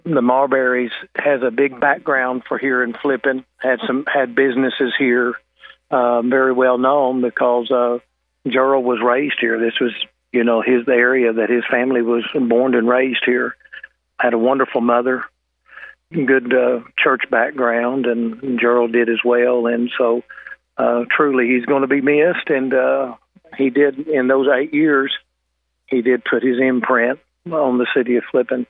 Hogan says Marberry was proud to be able to serve as the mayor of the city he grew up in and occupy the same office as his uncle, the late Bob Marberry.